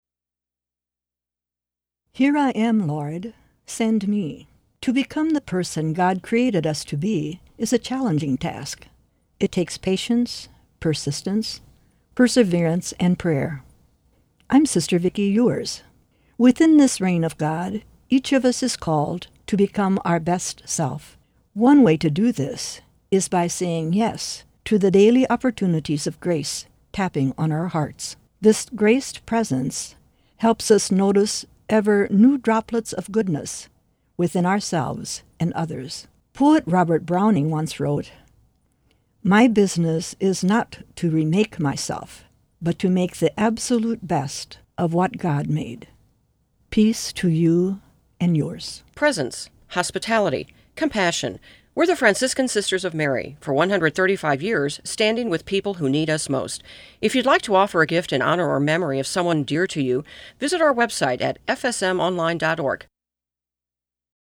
Each month, FSM share a one-minute message of hope, joy, grace or encouragement on the St. Louis classical music radio station.